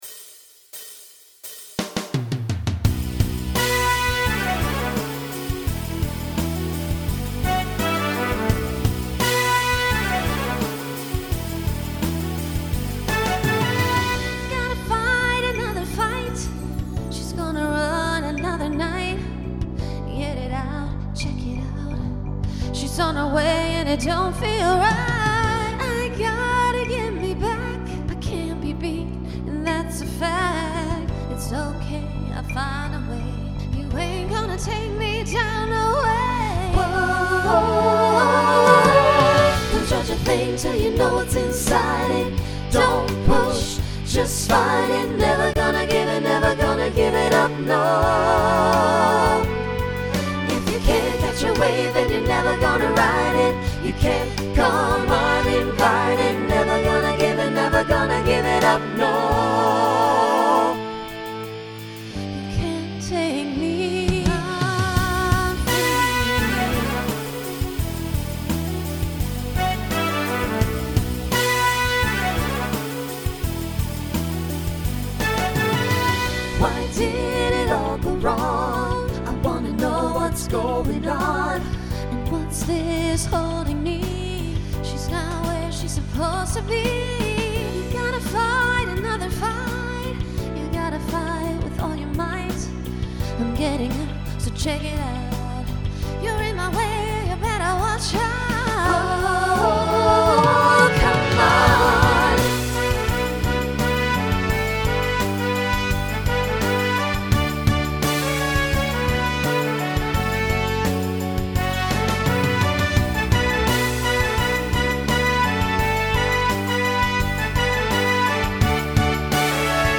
Voicing SATB Instrumental combo Genre Pop/Dance
Mid-tempo